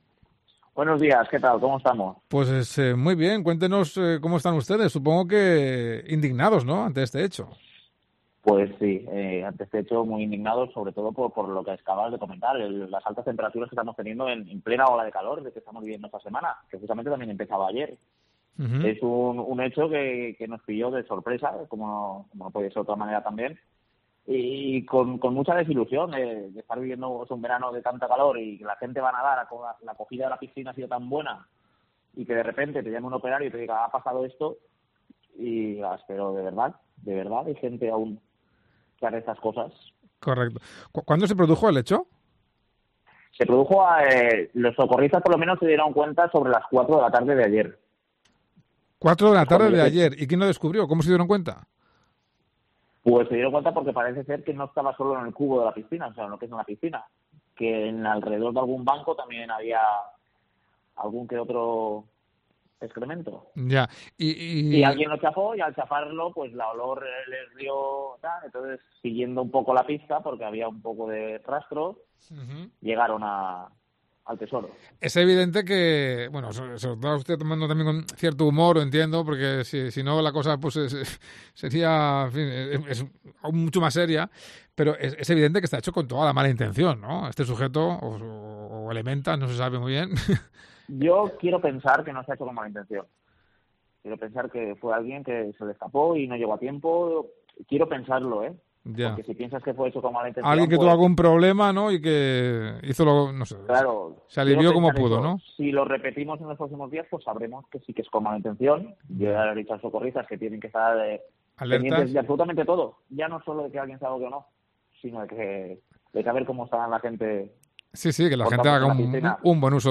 Miguel Marín, el alcalde de Anna, habla con COPE Valencia